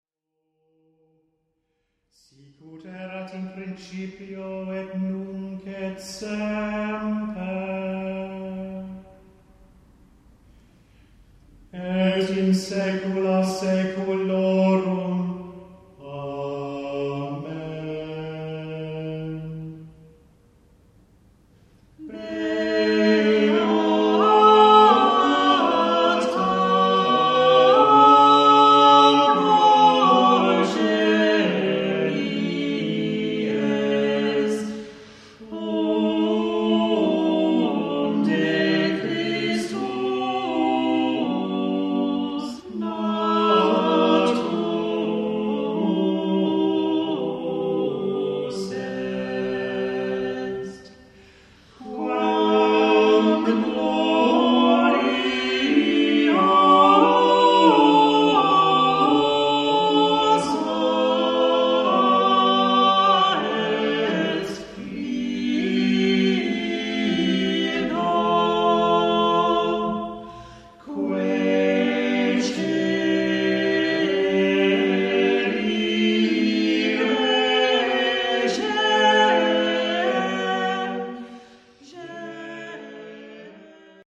Beata progenies – Power. This beautiful antiphon by Leonel Power is a good example of the Contenance Anglais harmonic style that revolutionised the sound of European music in the early 1400s. We perform it with its associated psalm to a plainsong tone.